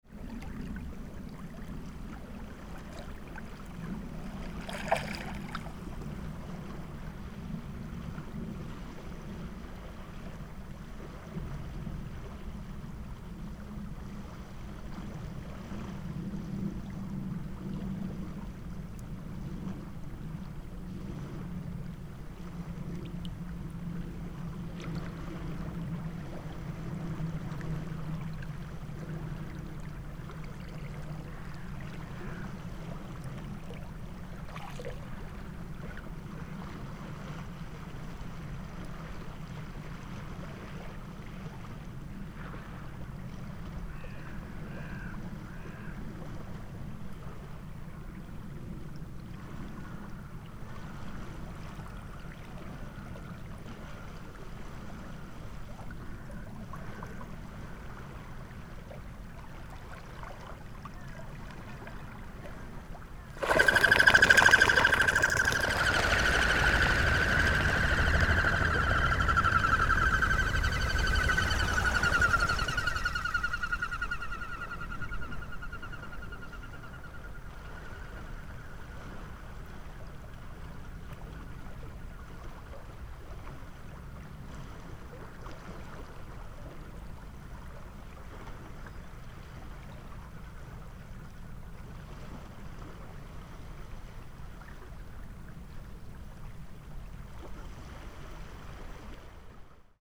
PFR07478, 130210, Common Goldeneye Bucephala clangula, males, wing sound, Mörtitz, Germany